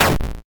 bigshot.mp3